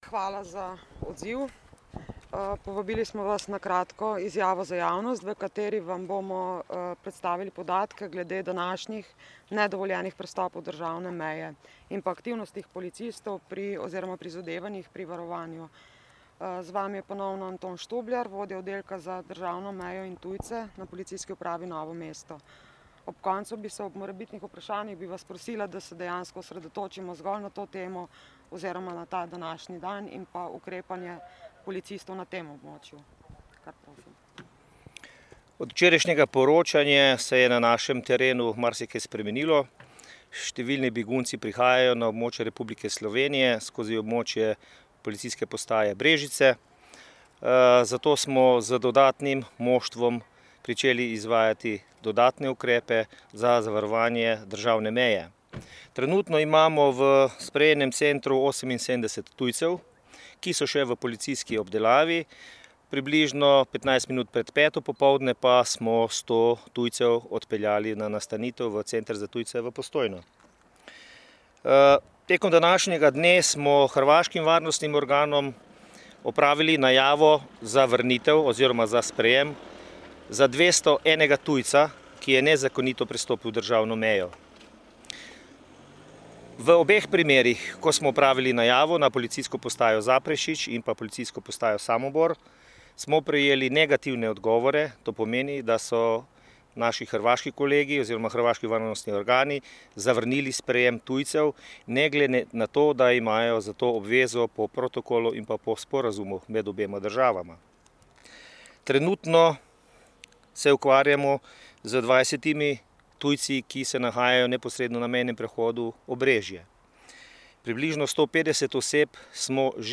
izjavi za javnost